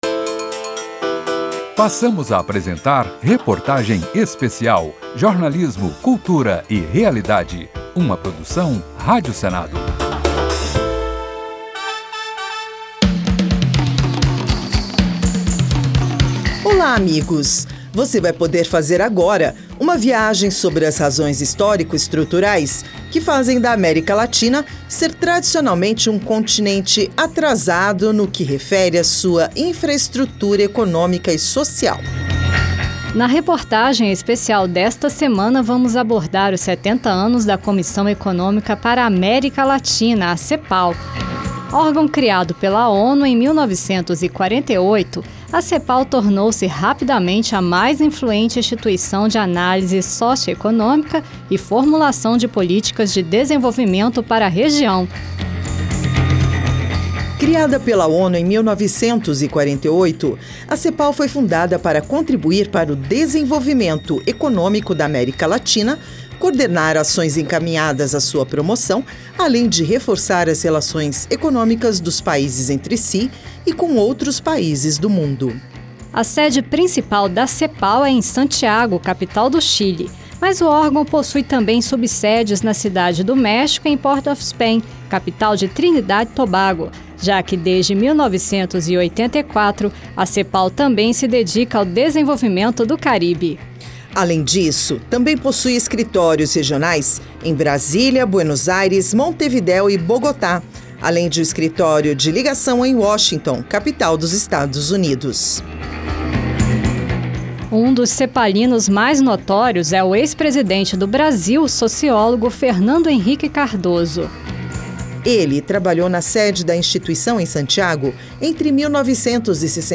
Reportagem Especial